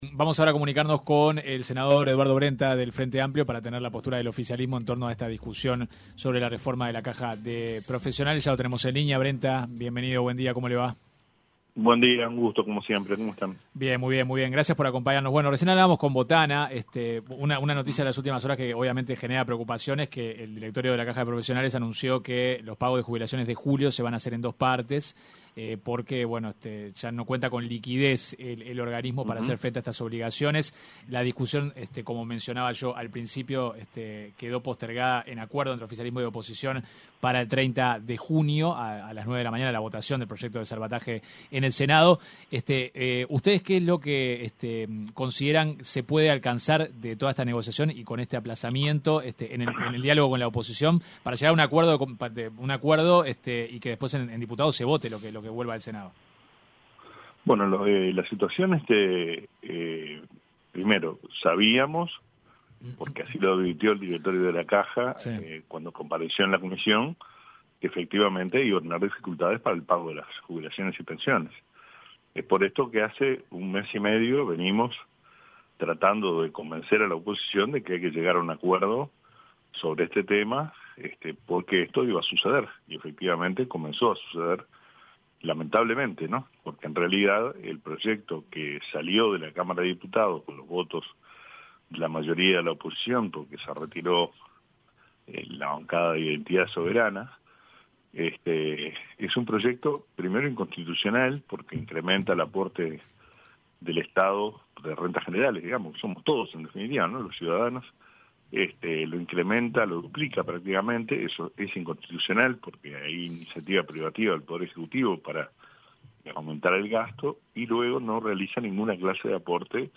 El senador del Frente Amplio, Eduardo Brenta, se refirió en entrevista con 970 Noticias, a las modificaciones que proponen desde la bancada oficialista para el proyecto de ley de salvataje para la Caja de Profesionales.